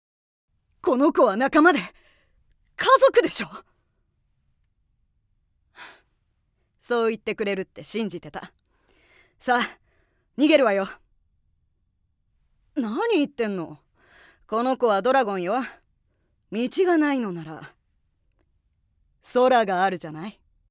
◆外画・女性ハンター◆